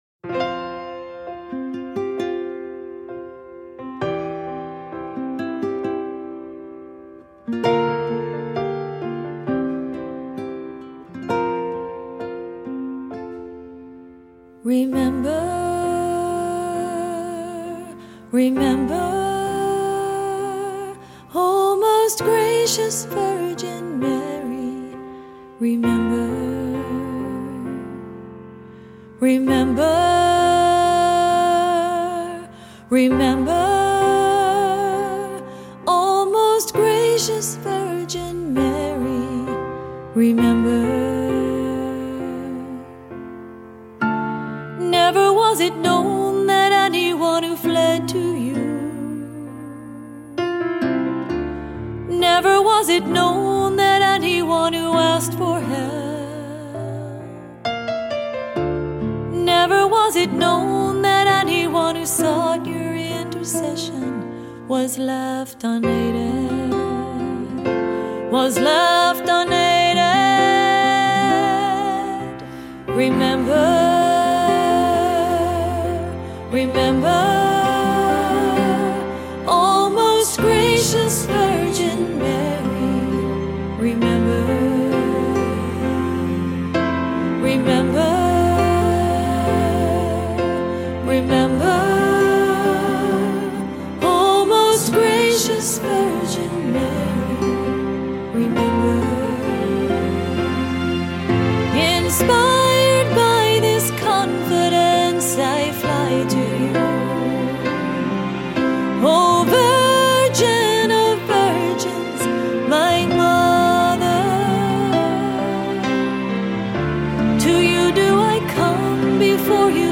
Voicing: SATB; Descant; Cantor; Assembly